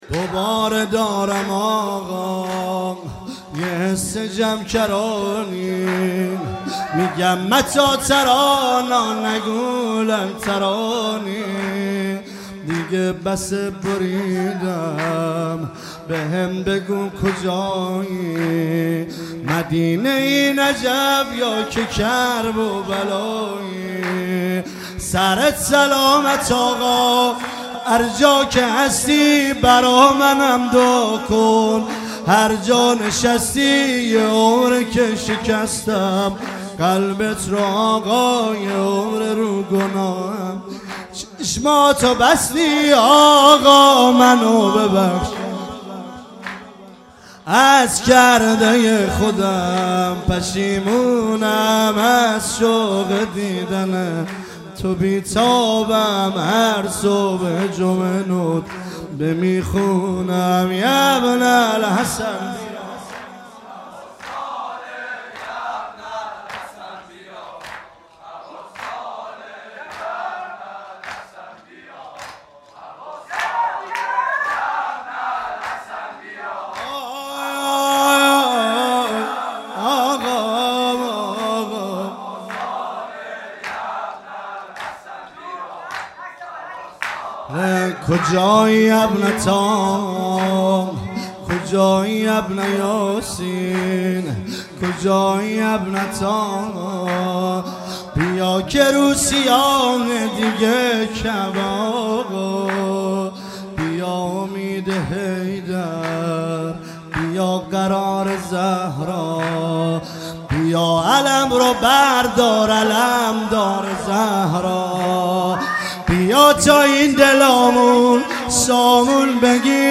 مراسم وفات حضرت معصومه ۱۳۹۶
هیئت حضرت زینب سلام الله علیها – نیشابور